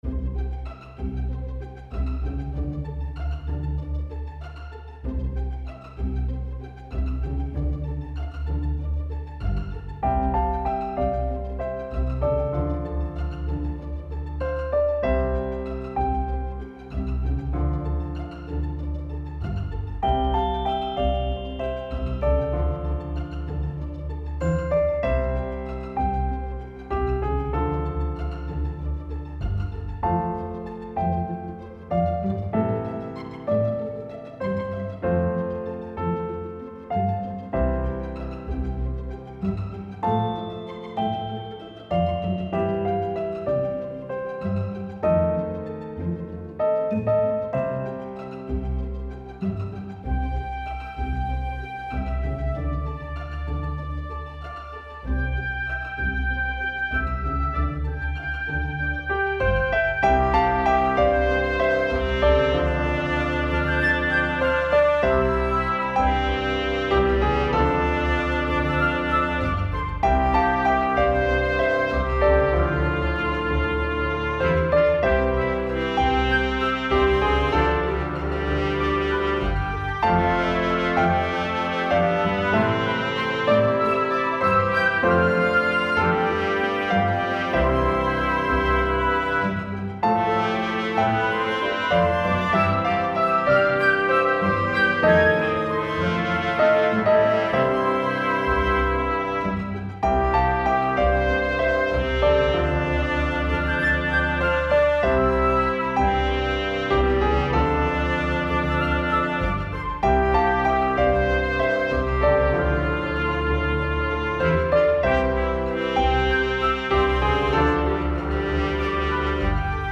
Return to Klisje Orchestra, 7:53.
default orchestra